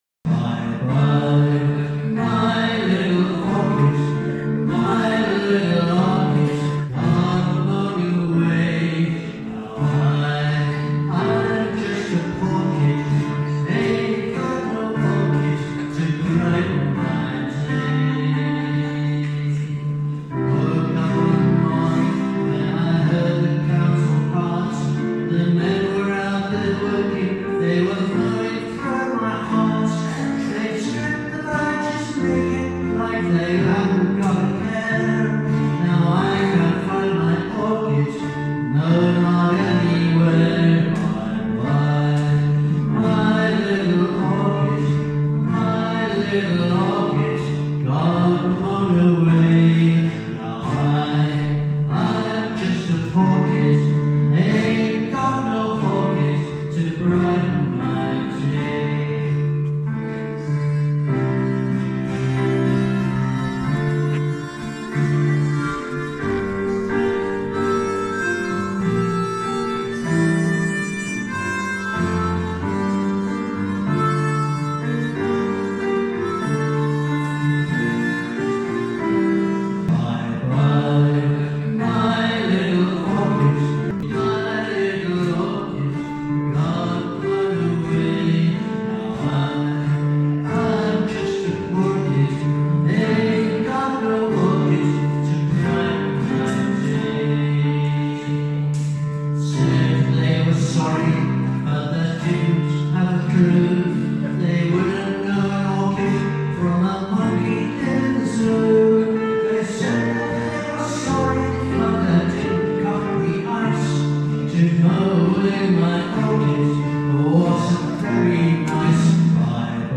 Concerts with Band of Brothers and Family